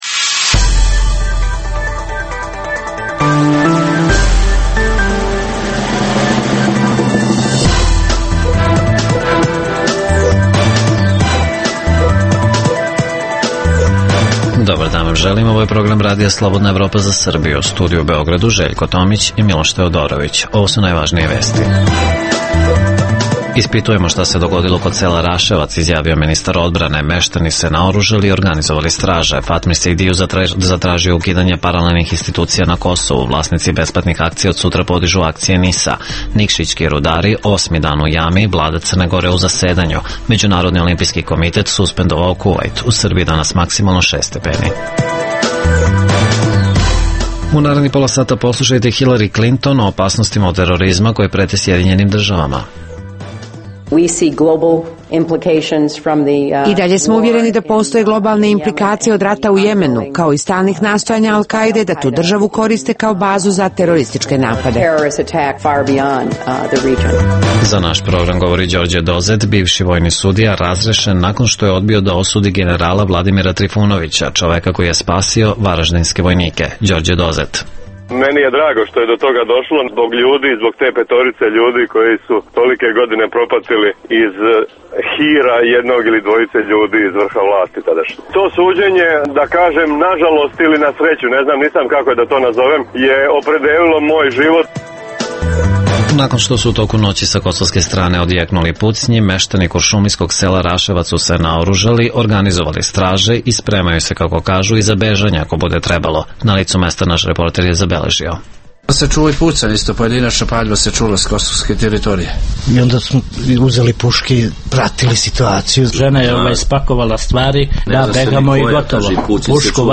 Naš reporter izveštava sa lica mesta. Takođe, govorimo o trijumfu generala Vladimira Trifunovića.